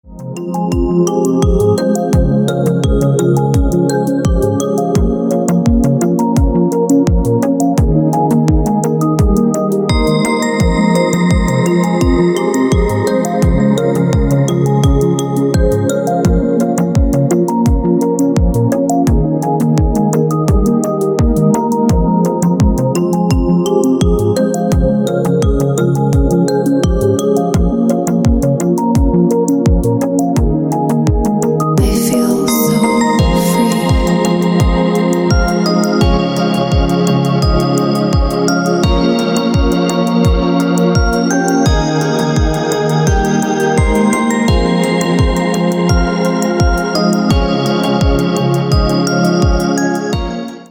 рингтоны и мелодии для будильника
Спокойные рингтоны